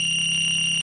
GameEnd.mp3